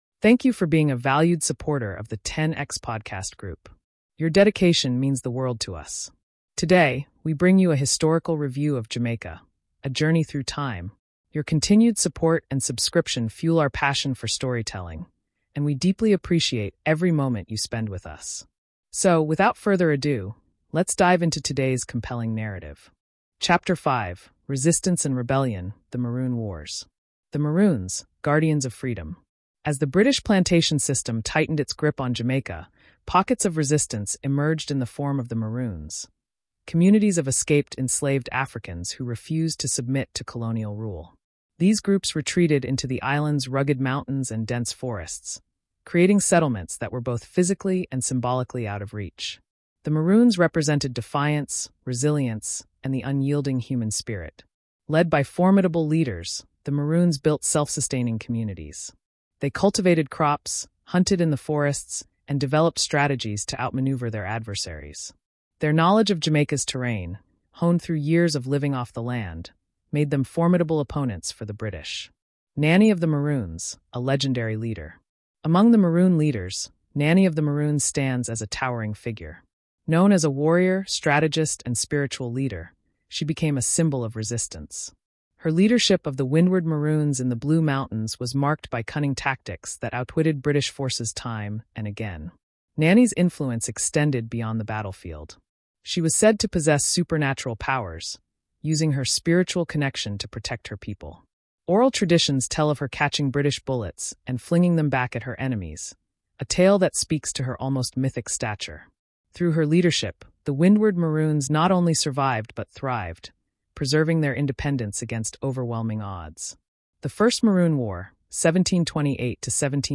Embark on a captivating journey through Caribbean history with Jamaica: A Journey Through Time — a powerful audio documentary series that explores the island’s resilient spirit, from its Taino roots to its global cultural influence. This immersive Caribbean podcast blends gripping history stories, authentic Jamaican music, and expert insights to celebrate the island’s heritage, identity, and resistance.